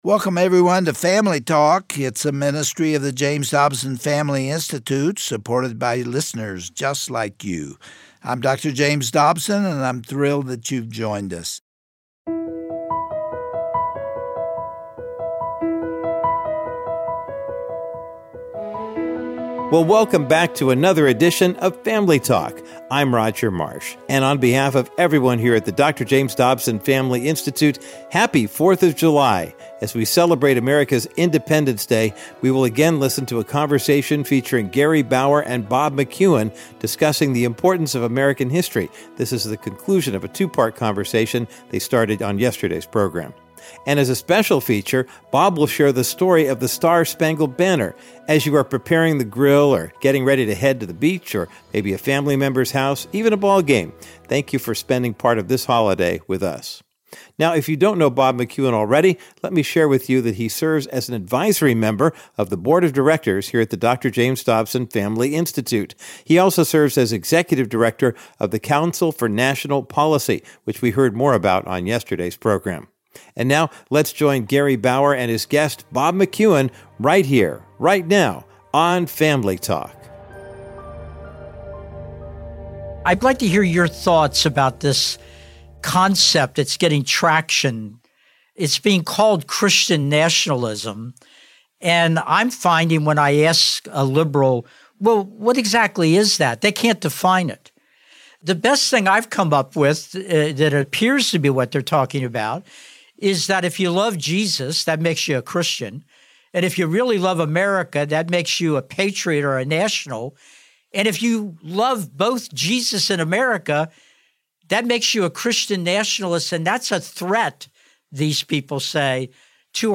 On today’s 4th of July edition of Family Talk, Gary Bauer concludes his stirring discussion with Bob McEwen, executive director of the Council for National Policy, about what makes America great. We must wake up to the warning signs as our society begins to crumble around us, and recognize the consequences that occur when a nation forsakes God.